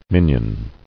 [min·ion]